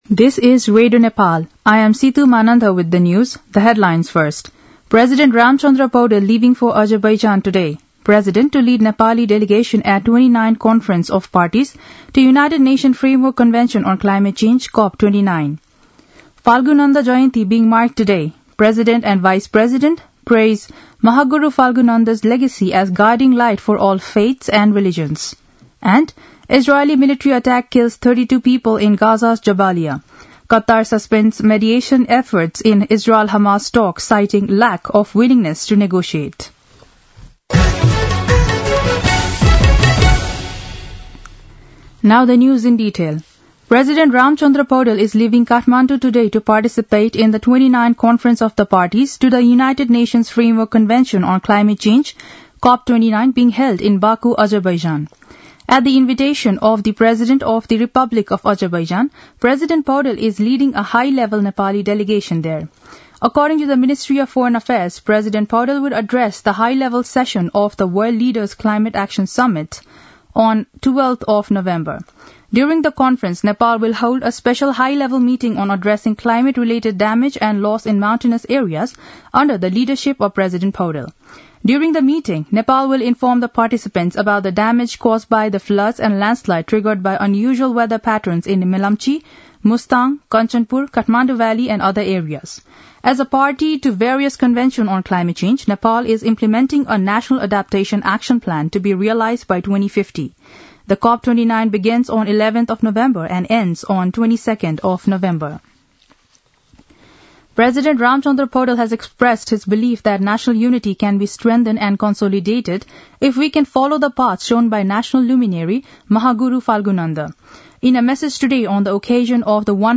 दिउँसो २ बजेको अङ्ग्रेजी समाचार : २६ कार्तिक , २०८१
2-pm-english-news-2.mp3